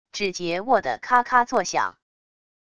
指节握得咔咔作响wav音频